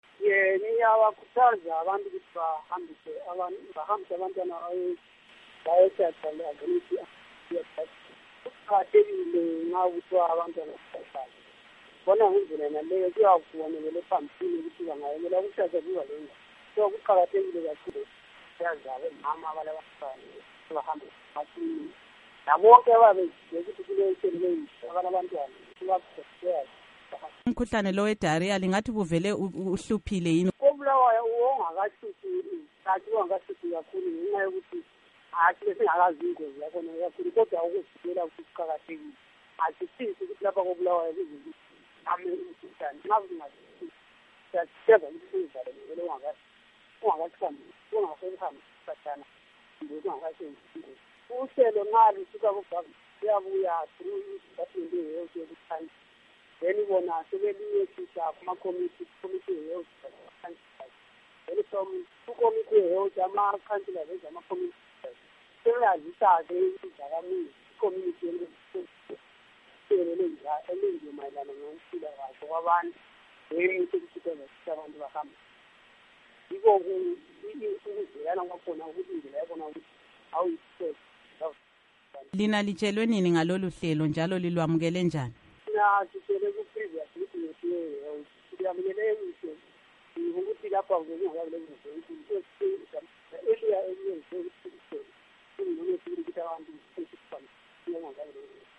Ingxoxo LoKhansila Ernest Rafa Moyo